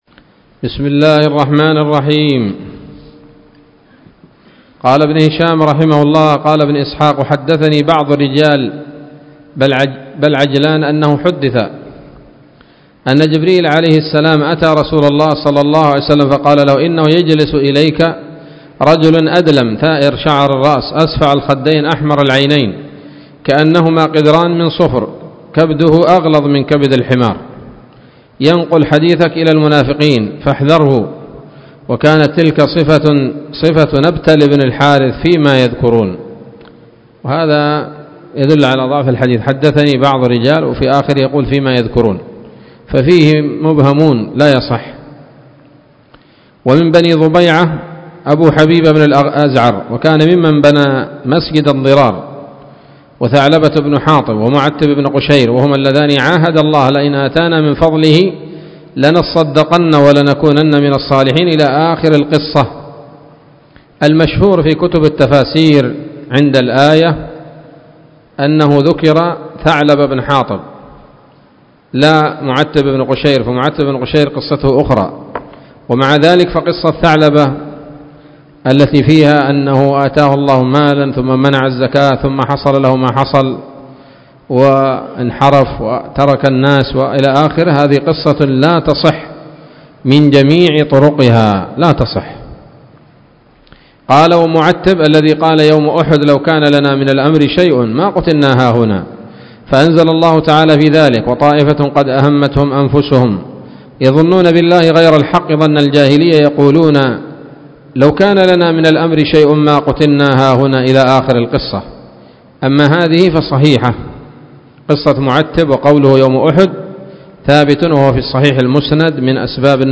الدرس الخامس والثمانون من التعليق على كتاب السيرة النبوية لابن هشام